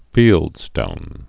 (fēldstōn)